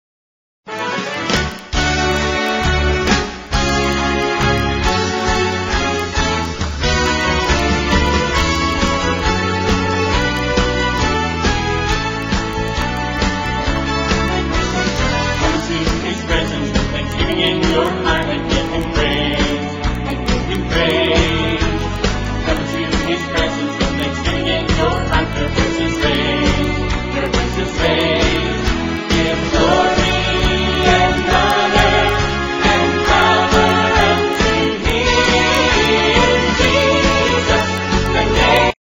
4 tracks WITH GUIDE VOCALS